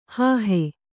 ♪ hāhe